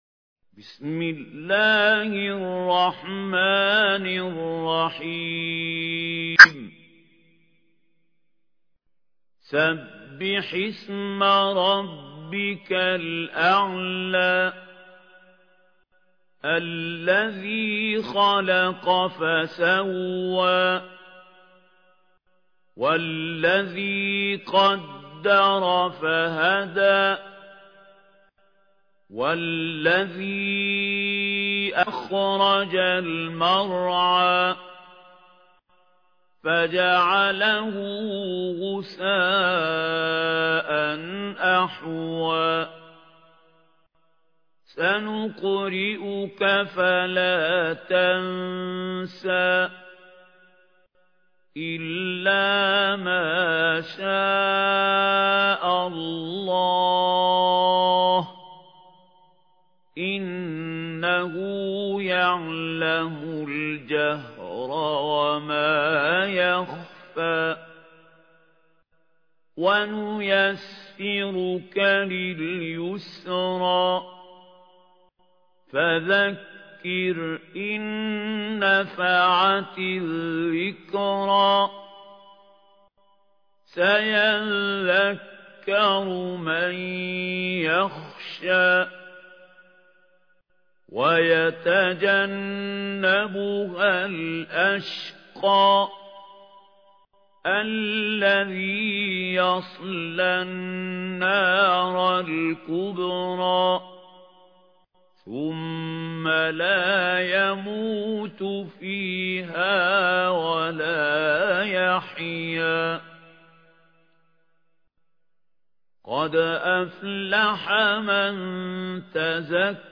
ترتيل
سورة الأعلى الخطیب: المقريء محمود خليل الحصري المدة الزمنية: 00:00:00